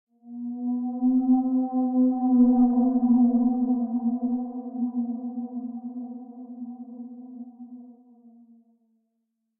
File:Sfx creature glowwhale call 03.ogg - Subnautica Wiki
Sfx_creature_glowwhale_call_03.ogg